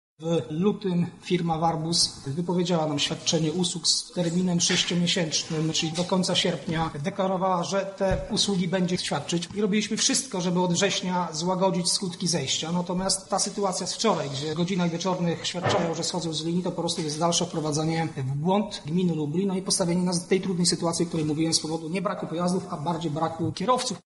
Konferencja ZTM